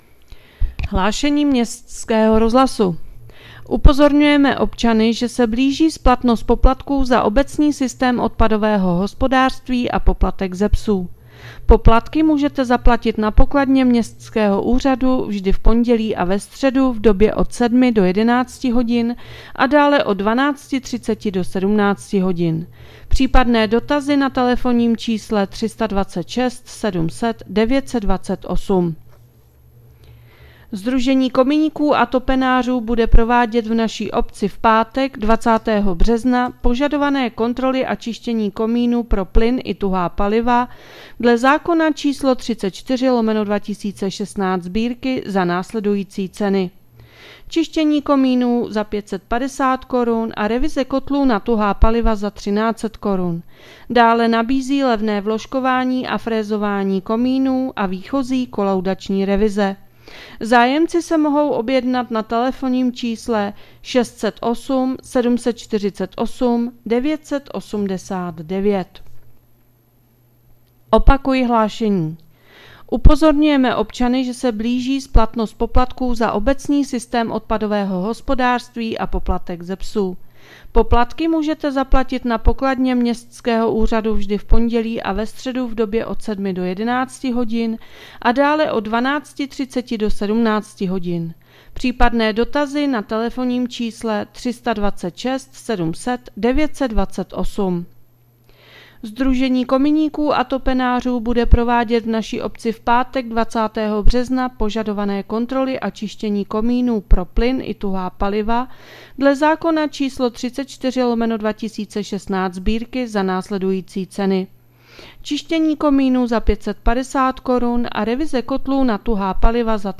Hlášení městského rozhlasu 18.3.2026